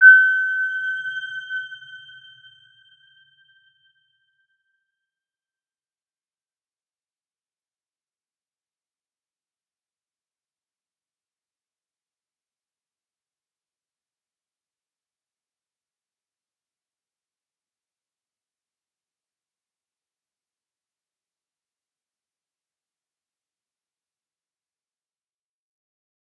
Round-Bell-G6-mf.wav